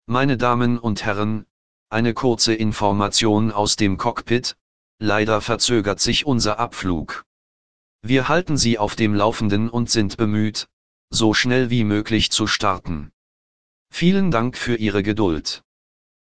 DepartureDelayed.ogg